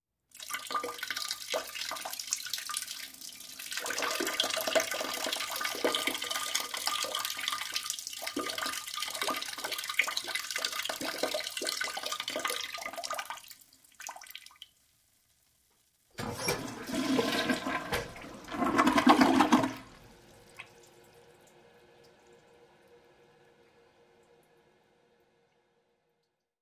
Звуки мочеиспускания
Шум струи в писсуаре